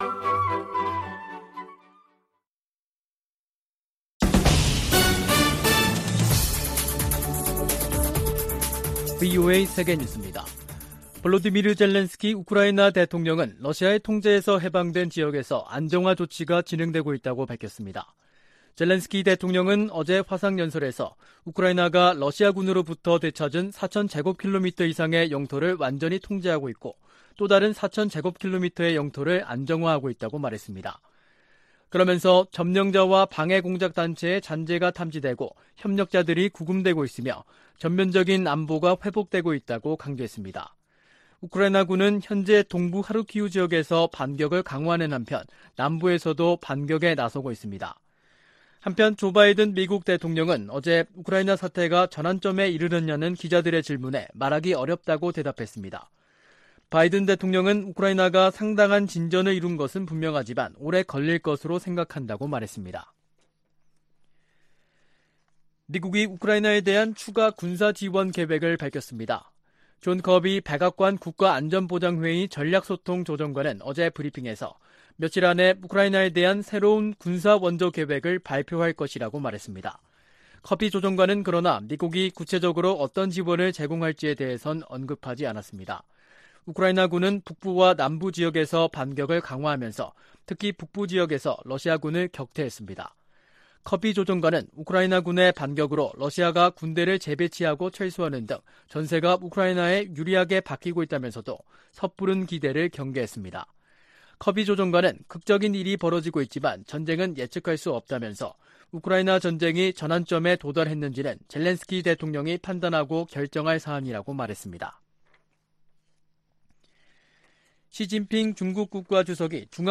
VOA 한국어 간판 뉴스 프로그램 '뉴스 투데이', 2022년 9월 14일 2부 방송입니다. 북한의 핵 무력정책 법제화가 유일지배체제의 근본적 모순을 드러내고 있다고 전문가들이 분석하고 있습니다. 미 상원의원들이 북한의 핵 무력정책 법제화에 우려와 비판의 목소리를 내고 있습니다. 유엔 인권기구가 북한 지도부의 코로나 규제 조치로 강제노동 상황이 더 악화했을 수 있다고 경고했습니다.